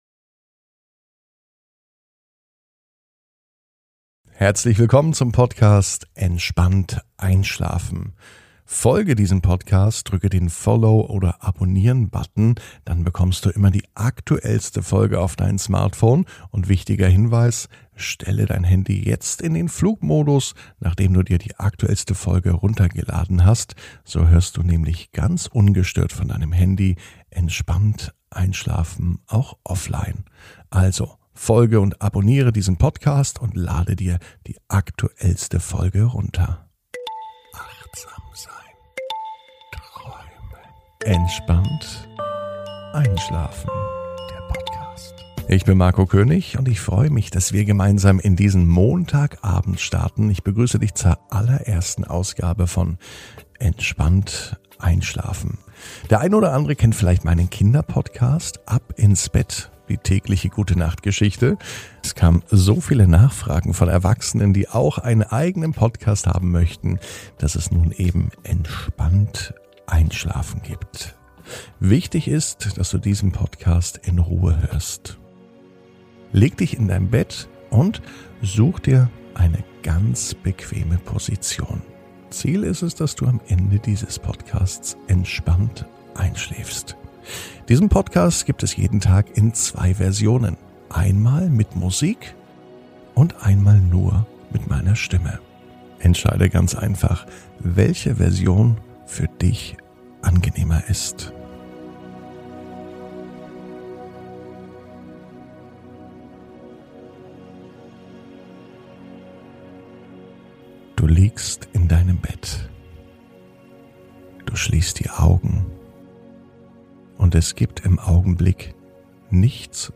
Entspannt einschlafen am Montag, 19.04.21 ~ Entspannt einschlafen - Meditation & Achtsamkeit für die Nacht Podcast